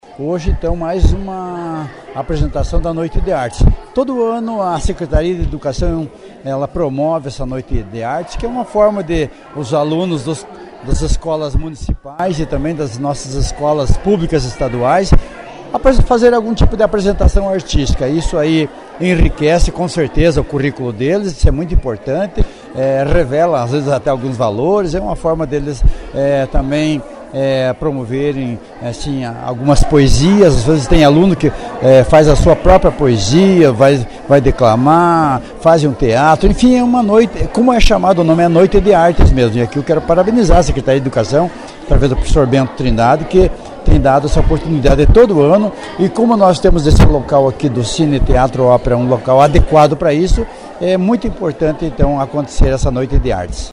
O prefeito da cidade de Porto União, Anízio de Souza, parabenizou a todos os envolvidos na organização e aos alunos que subiram no palco para fazer a sua apresentação.
HOJE-ARTES-02-PREF-ANIZIO-FALA-DO-EVENTO-E-PARABENIZA-TODOS-PELO-ORGANIZAÇÃO-E-APRESENTAÇÃO.mp3